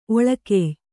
♪ oḷakey